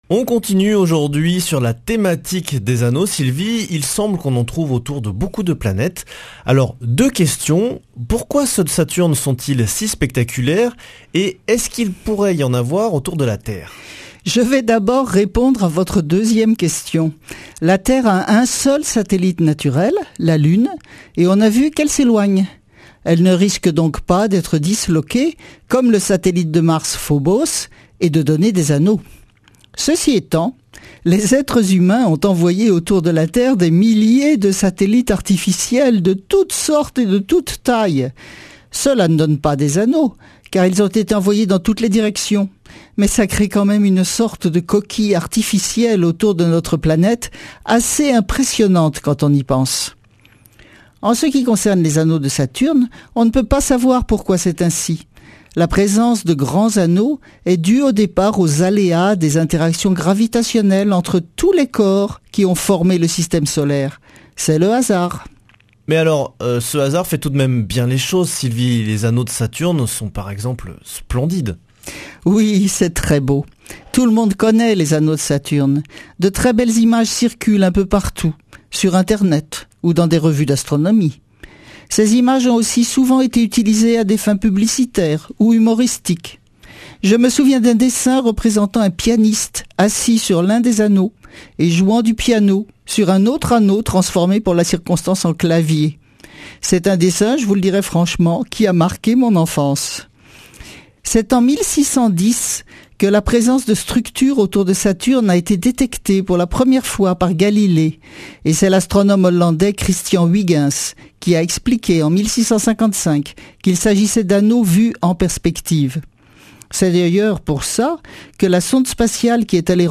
lundi 20 mars 2017 Chronique Astrophysique Durée 3 min
Speech